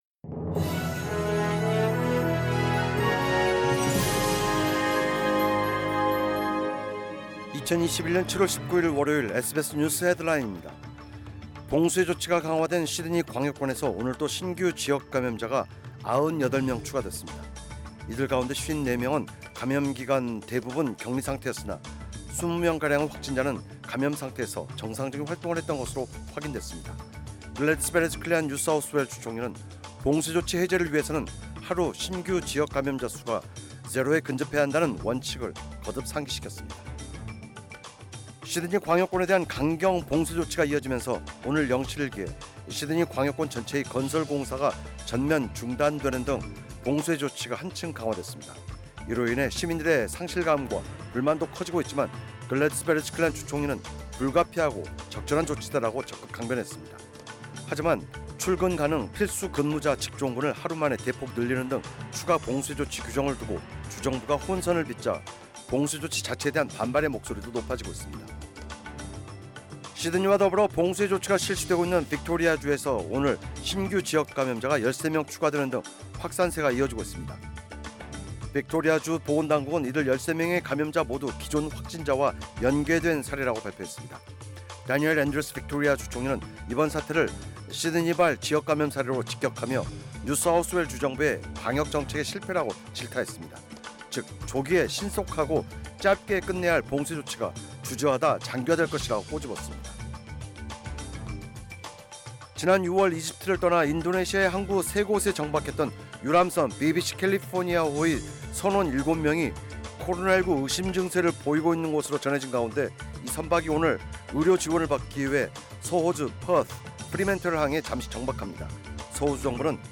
2021년 7월 19일 월요일 SBS 뉴스 헤드라인입니다.